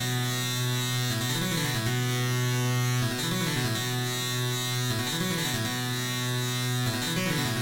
华尔道夫布洛菲德简单的Riff在126bpm的Bminor
描述：华尔道夫布洛菲德简单的riff在126bpm的Bminor中。
Tag: 126 bpm Electronic Loops Synth Loops 1.28 MB wav Key : B